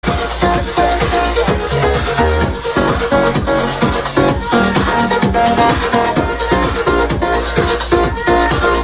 Killer track sounds similar to Klaas style